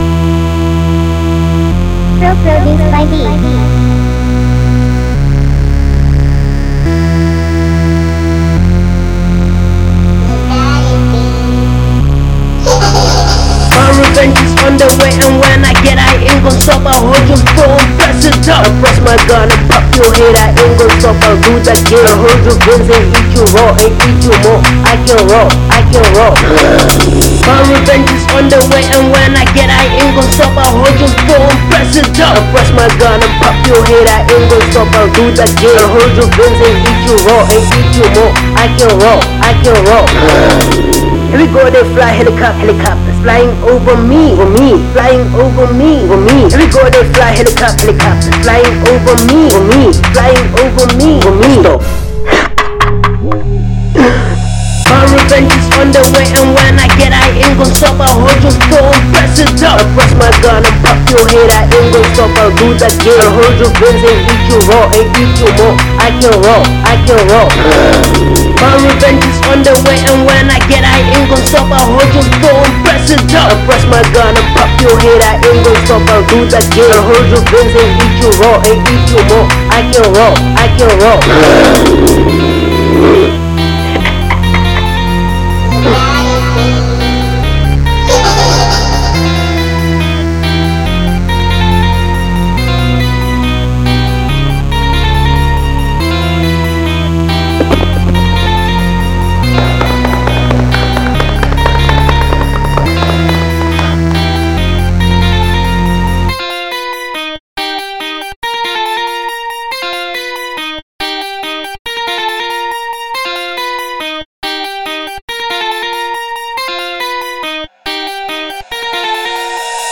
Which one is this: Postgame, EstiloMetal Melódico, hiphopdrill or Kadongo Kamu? hiphopdrill